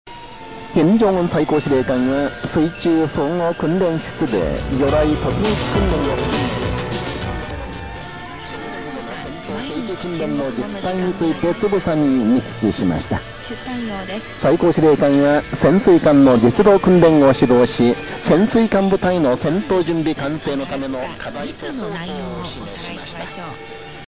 受信音サンプル
ベランダの手すりにアンテナを接続して受信
短波モードでの受信音 20秒 (mp3ファイル:712KB)
（北朝鮮の「朝鮮の声日本語放送」を受信)
short_wave2.mp3